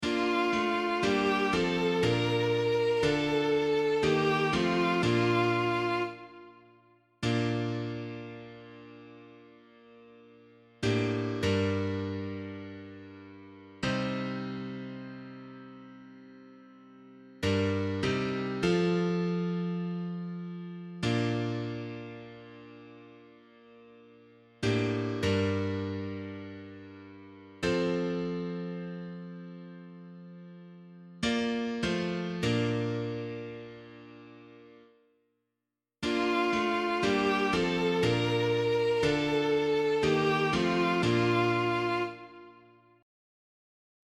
Responsorial Psalm     Psalm 69 (68): 8-10, 14+17, 33-35
B♭ major